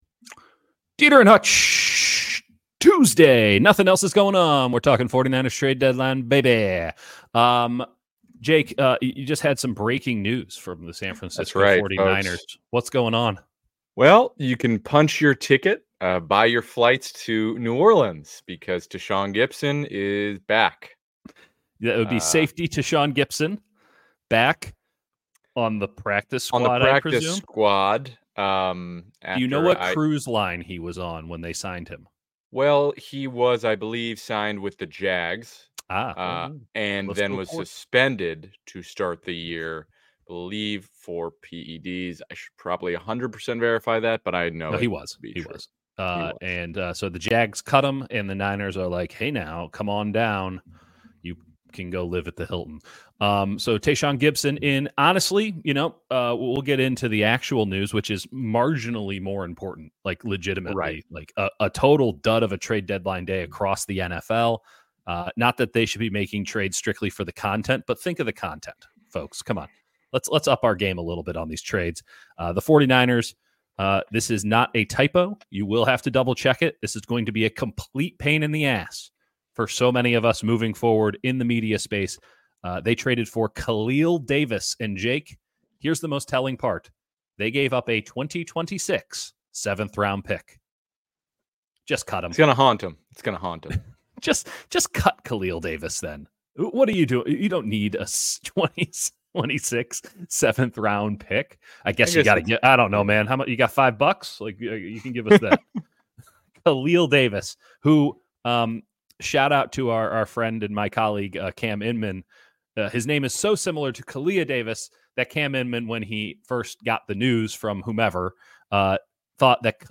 49ers Trade Deadline LIVE Reaction